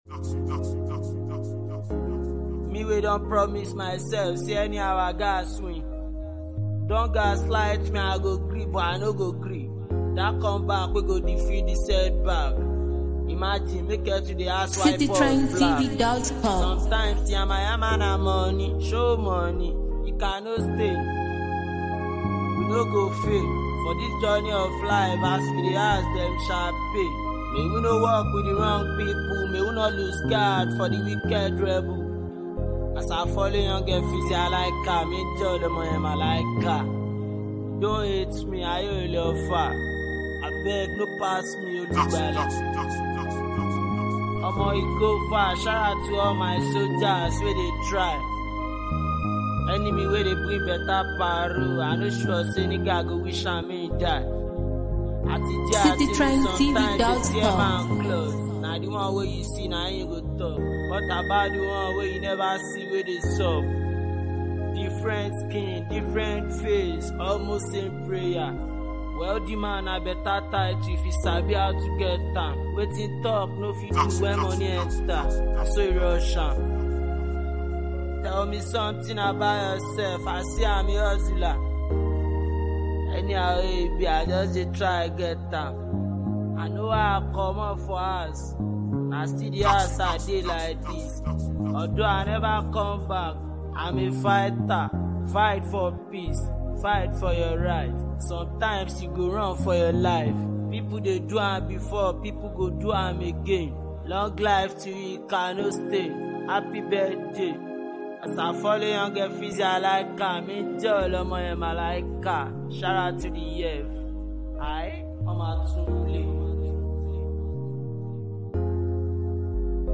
a catchy and energetic track
Known for his dynamic flows and playful lyrical style